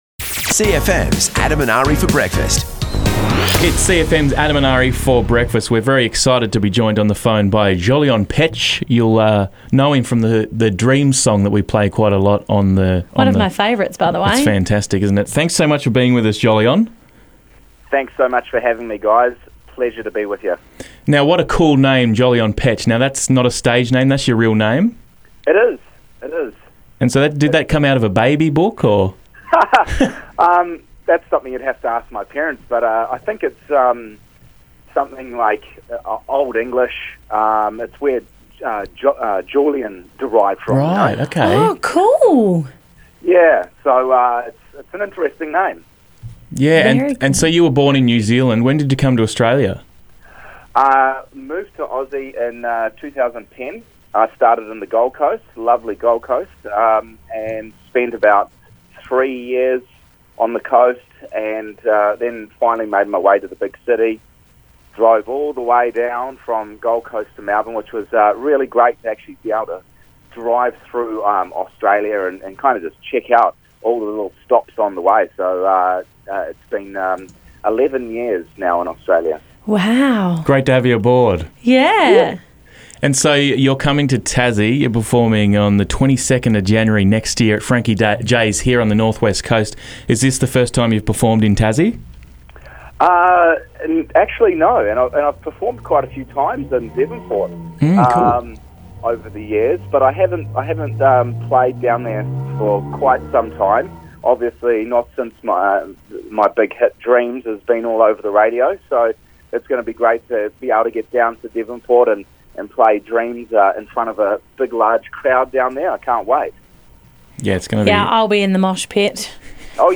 CLIP: Interview